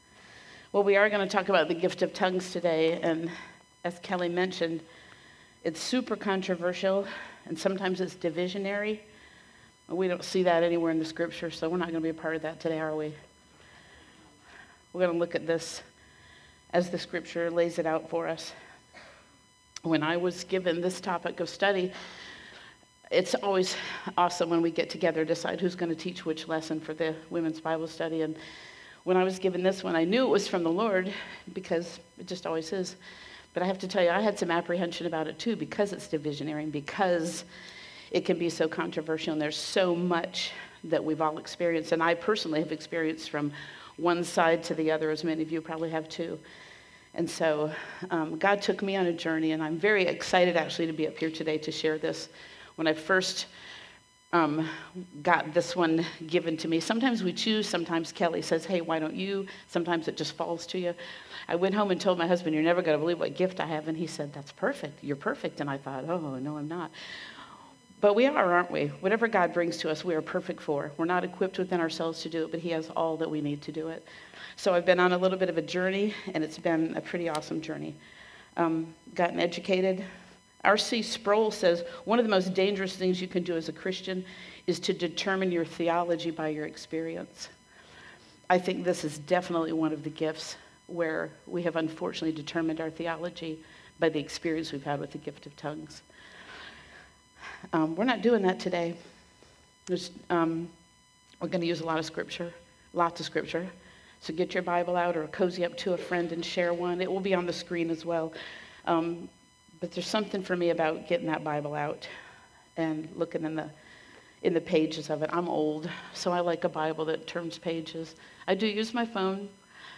A message from the series "Women of the Word."